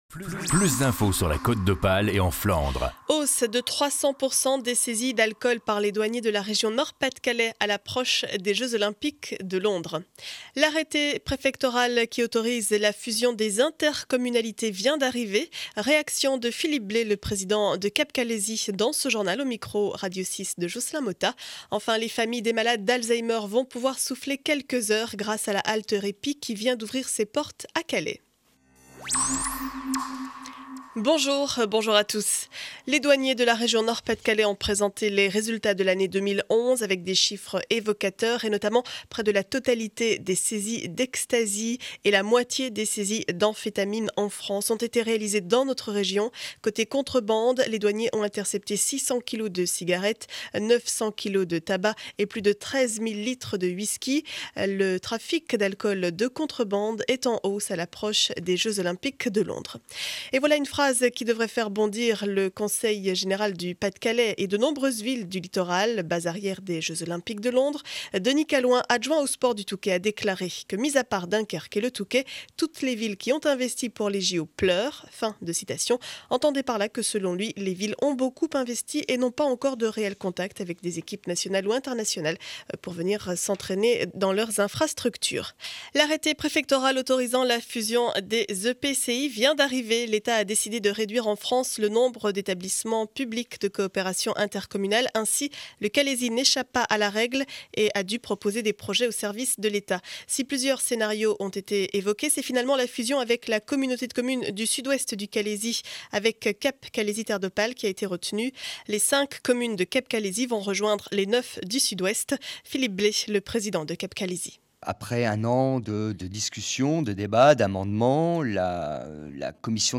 Journal du mercredi 22 février 2012 7 heures 30 édition du Calaisis.